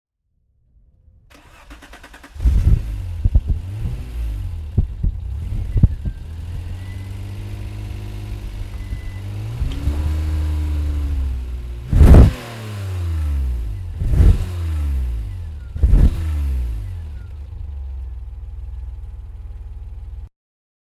Alfa Romeo 164 3.0 V6 (1990) - Starten und Leerlauf